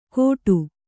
♪ kōṭu